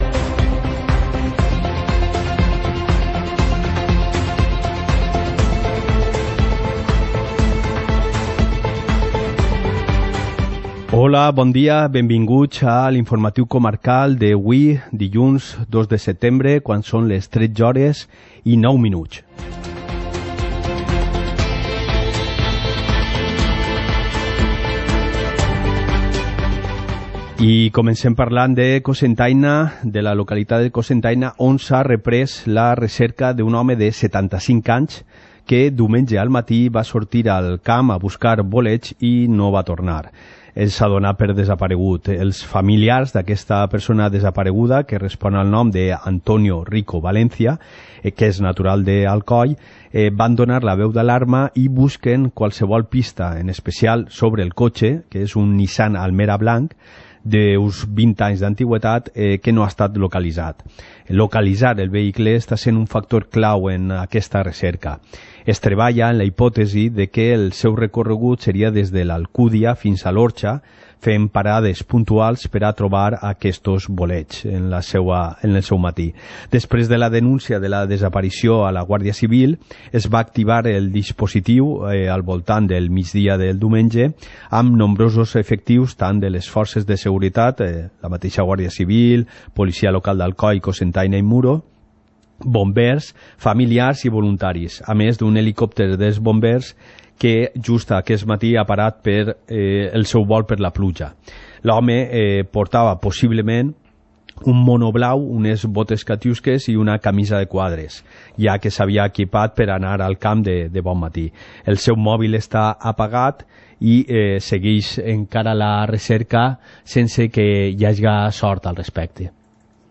Informativo comarcal - lunes, 02 de septiembre de 2019